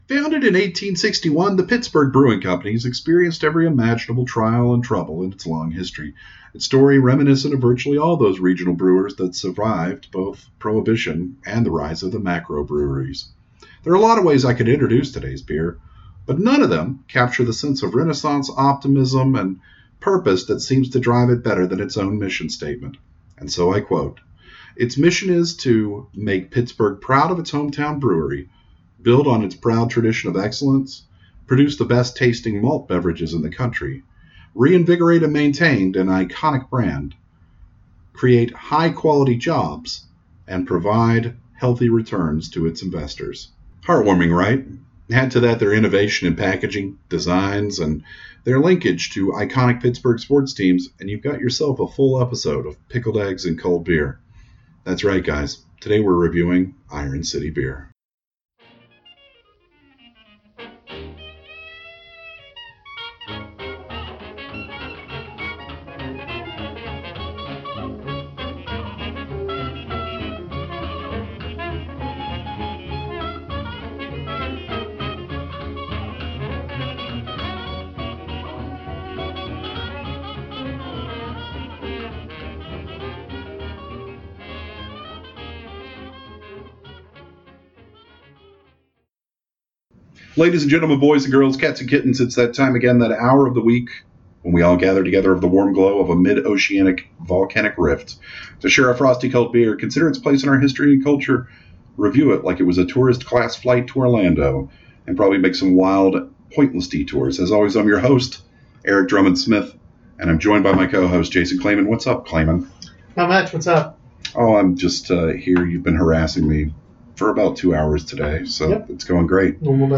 They laugh a lot.